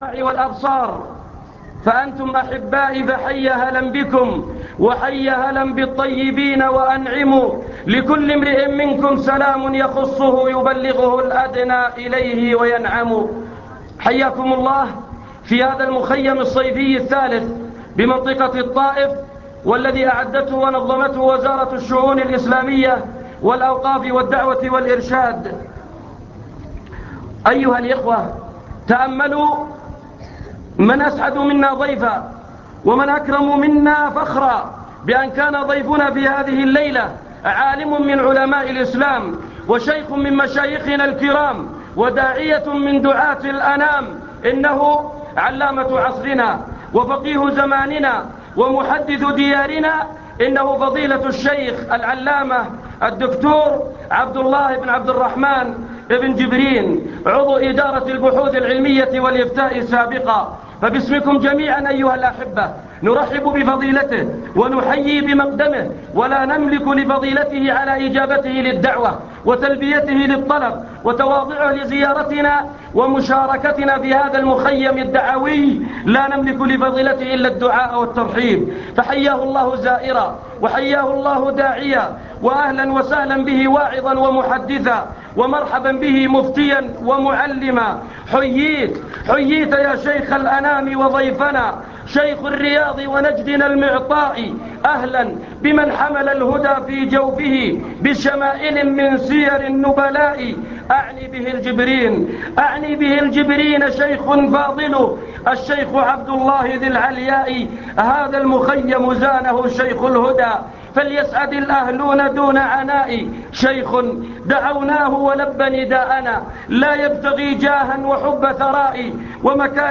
المكتبة الصوتية  تسجيلات - لقاءات  لقاء مفتوح بمخيم الردف
تقديم